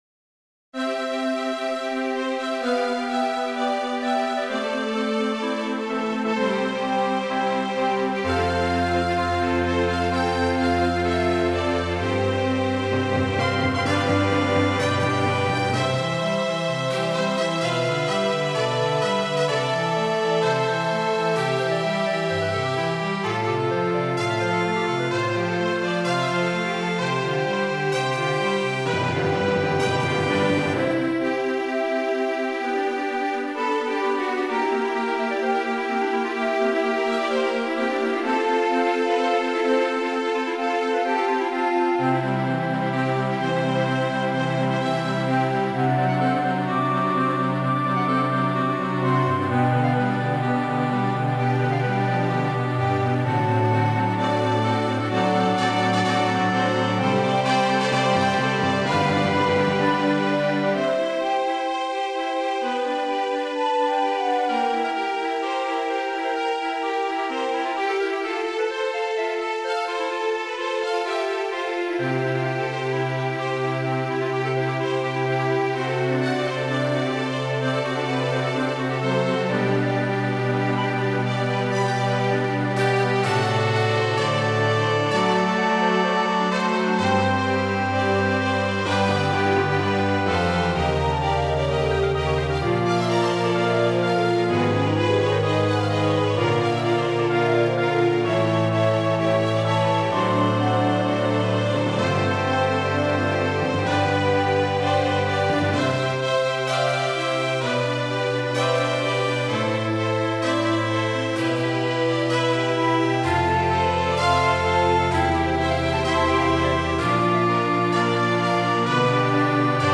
Orchestra Ver.